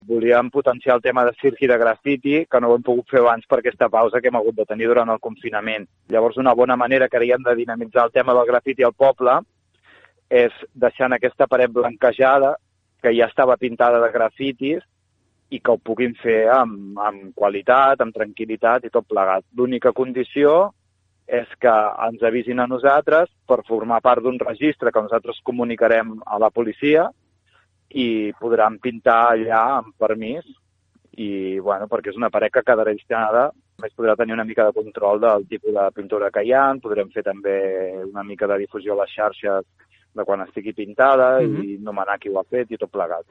ha explicat en una entrevista a aquesta emissora que per fer el taller s’ha blanquejat la paret lateral de l’Institut de la Font del Ferro de Palafolls